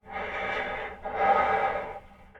Player_UI [42].wav